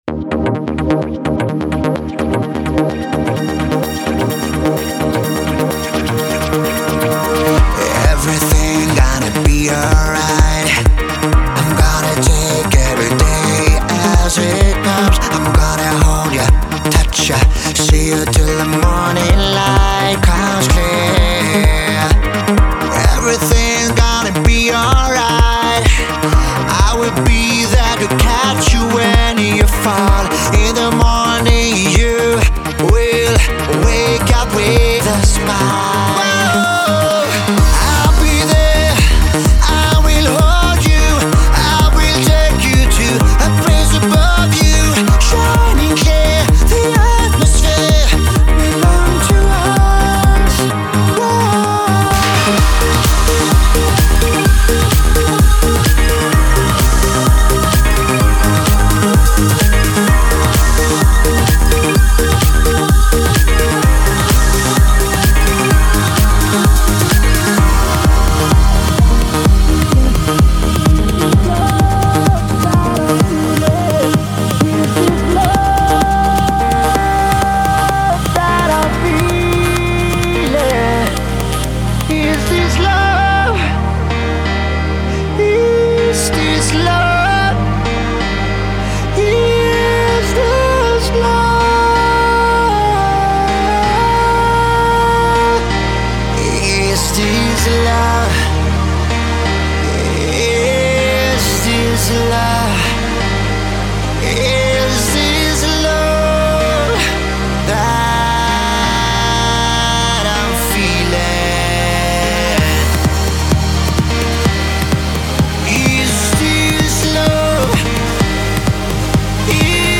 Жанр: Electro House